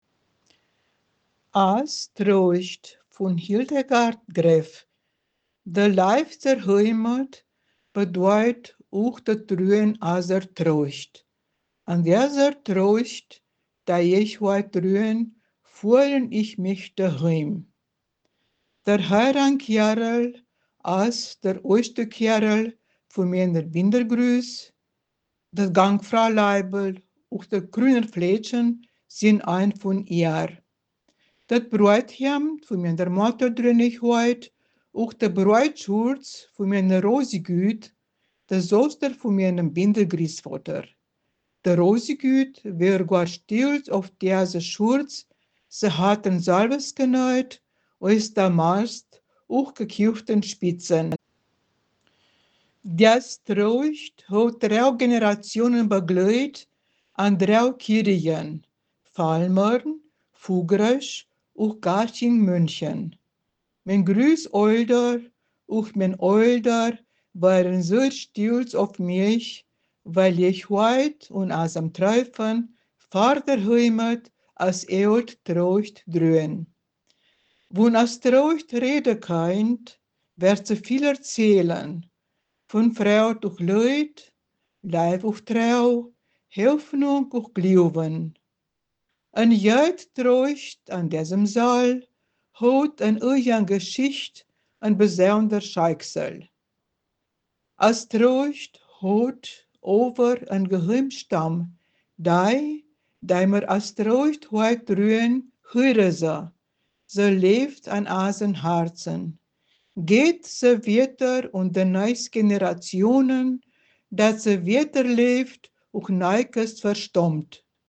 Ortsmundart: Felmern